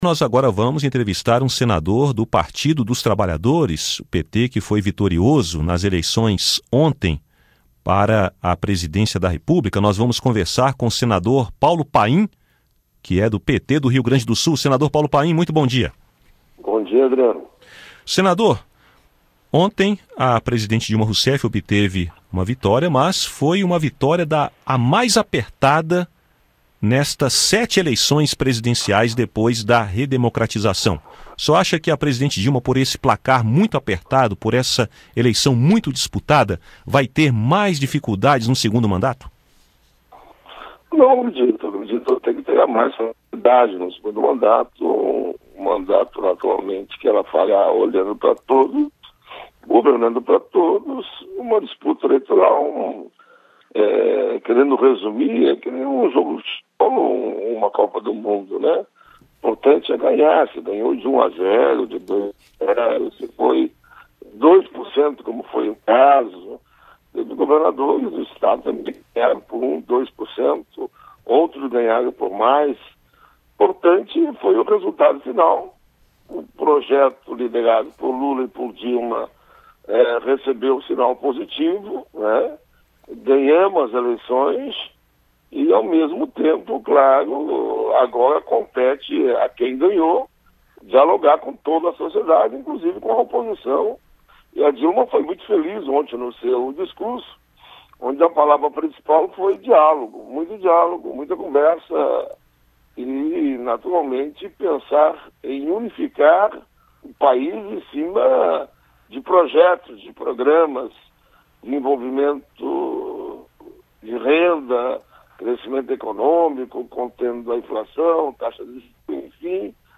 Em entrevista à Rádio Senado na segunda-feira (27), o senador Paulo Paim (PT-RS) comentou a reeleição da presidenta da República, Dilma Roussef, no domingo. De acordo com o parlamentar, o governo, que é maioria no Congresso, deverá dialogar com a oposição.